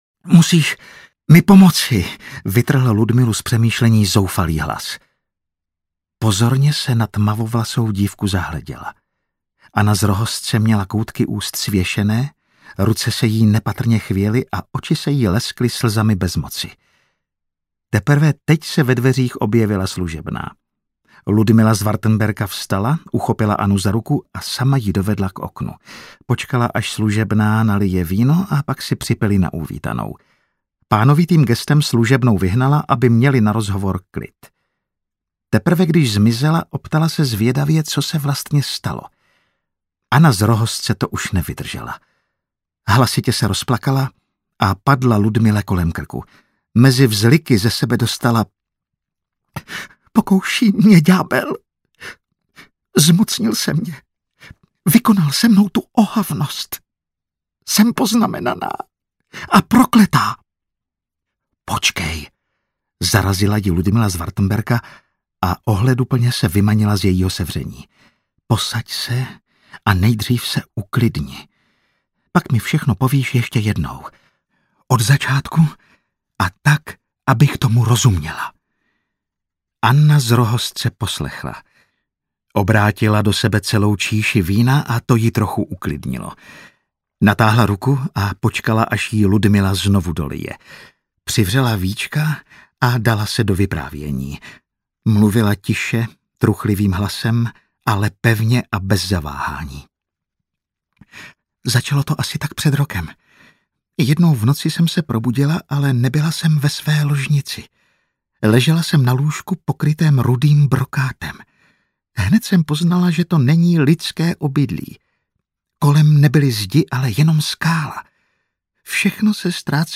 Apage Satanas audiokniha
Ukázka z knihy